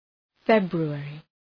Προφορά
{‘febru:,erı}